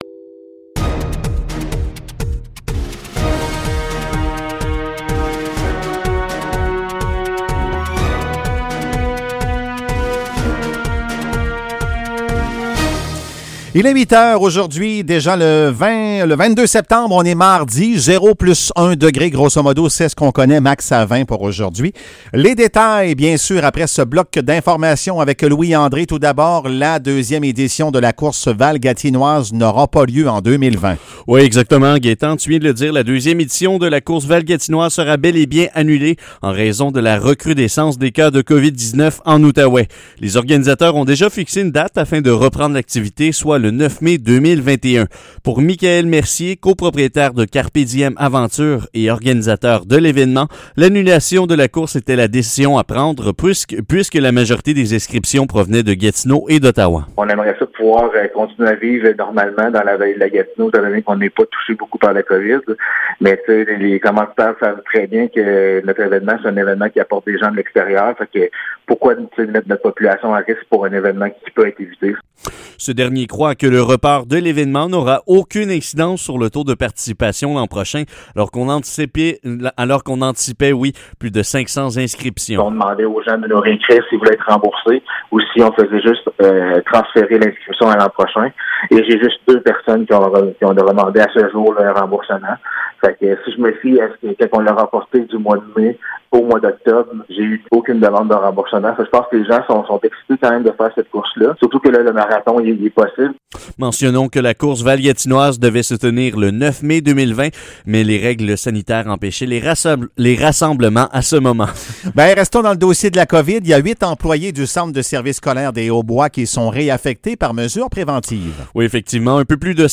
Nouvelles locales - 22 septembre 2020 - 8 h